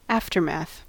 Ääntäminen
IPA: [ˈfɔlɡə]